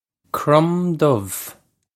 Crom Dubh Krum Duv
This is an approximate phonetic pronunciation of the phrase.